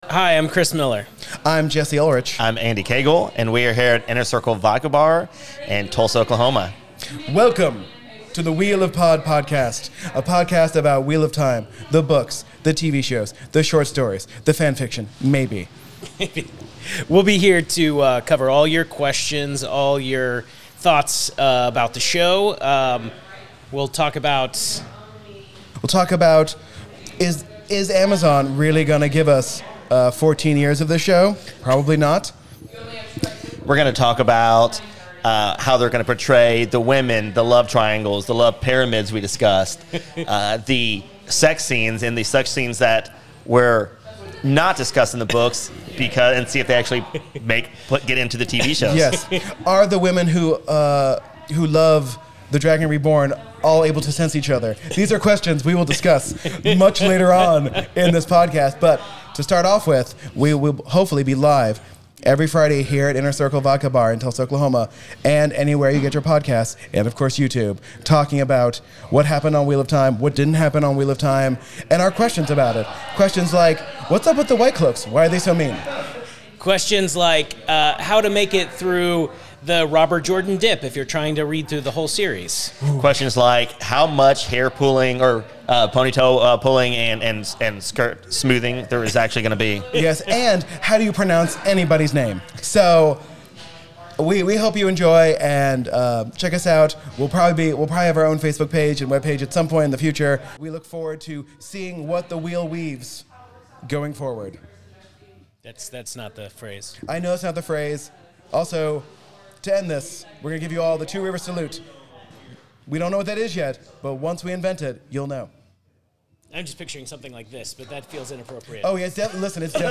A podcast about the Wheel of Time TV show and the books! Join us as multiple hilarious nerds talk about the newest fantasy series!